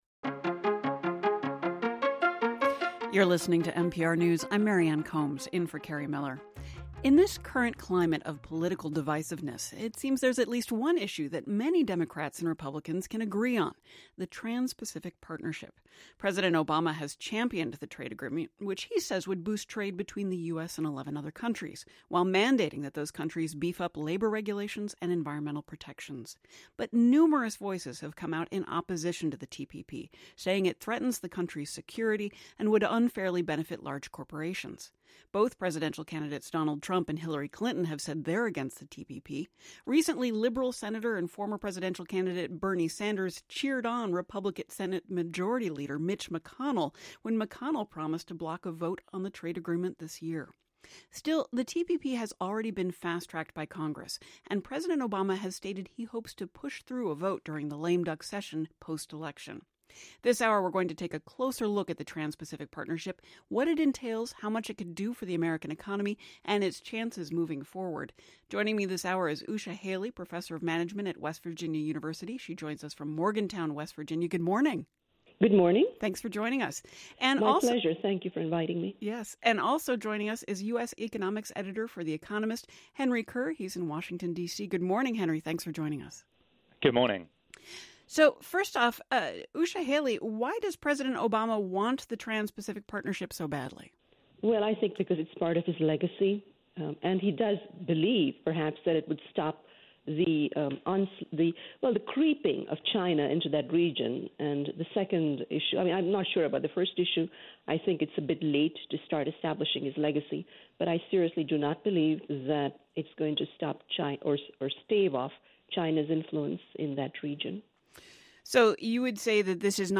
hour-long interview, " Boon or Disaster: Unpacking the TPP ", Minnesota Public Radio, broadcast nation-wide over NPR